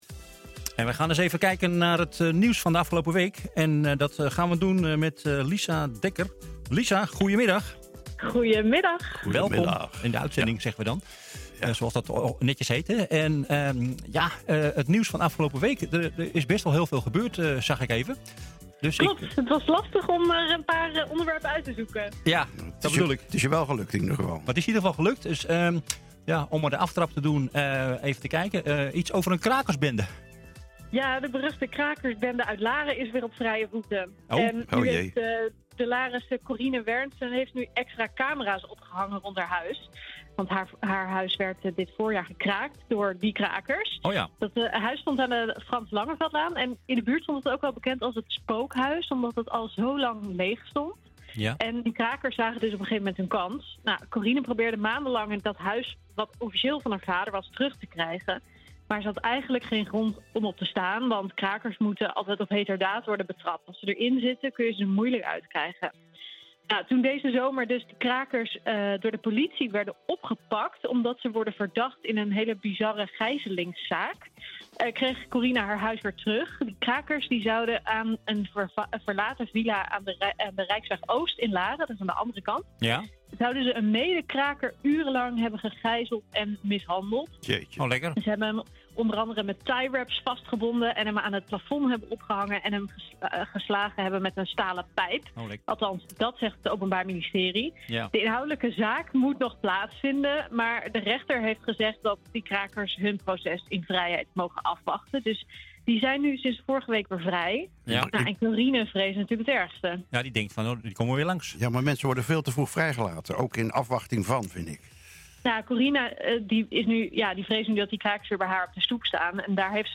Iedere zaterdag geeft een redacteur/verslaggever van NH in De Lunchclub een overzicht van wat er afgelopen week in het nieuws was.